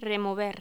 Locución: Remover
voz
Sonidos: Voz humana